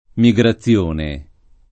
[ mi g ra ZZL1 ne ]